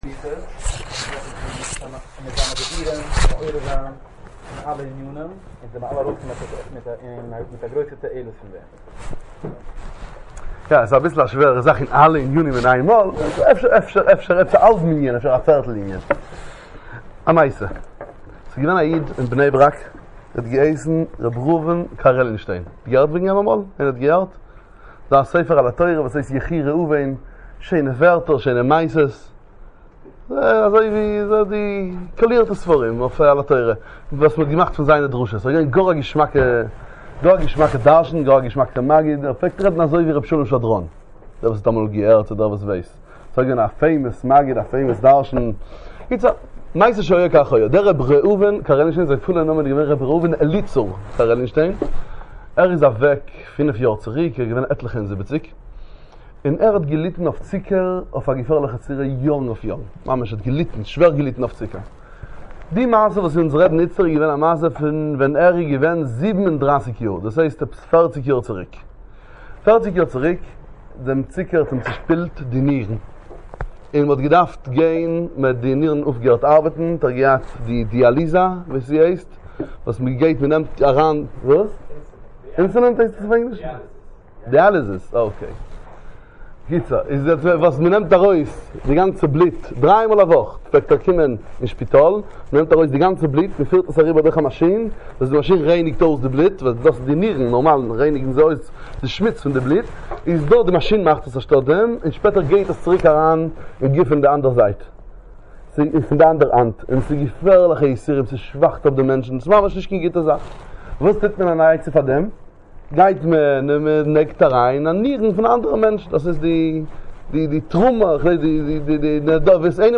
דברי חיזוק בישיבת מאירים בלונדון